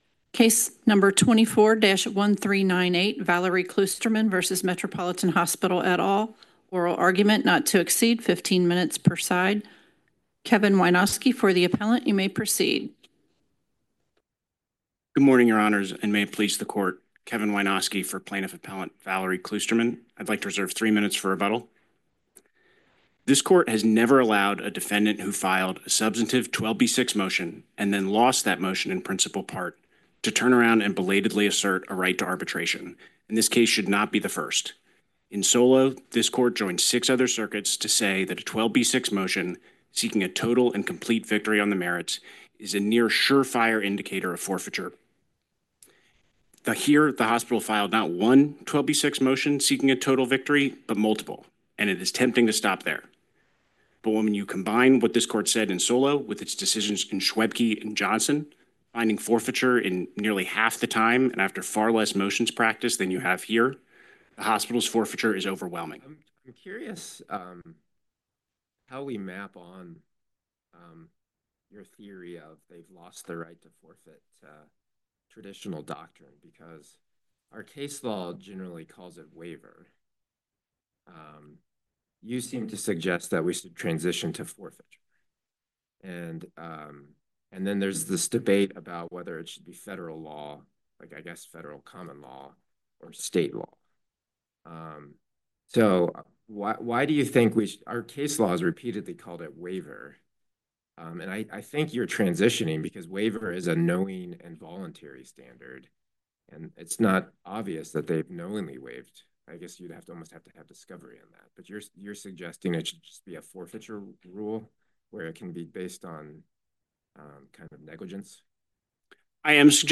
U.S Court of Appeals for the Sixth Circuit